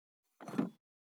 196,グラス,コップ,工具,小物,雑貨,コトン,トン,ゴト,ポン,
効果音物を置く